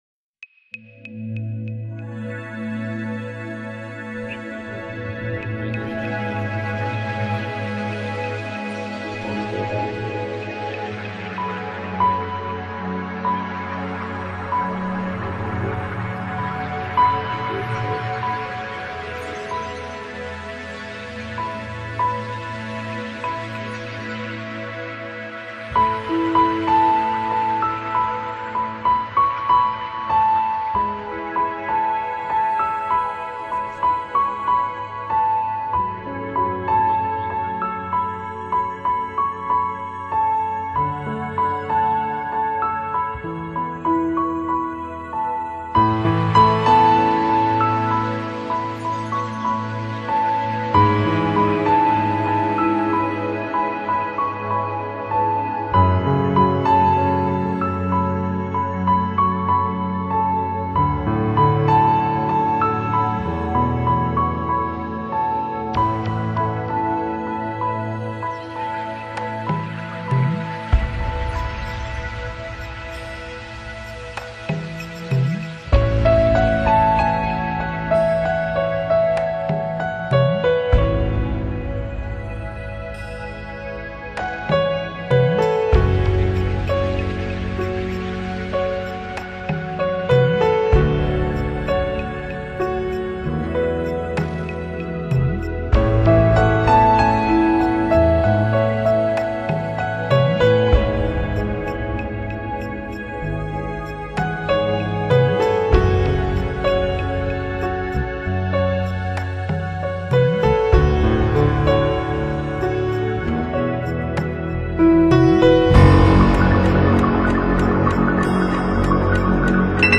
爵士女钢琴家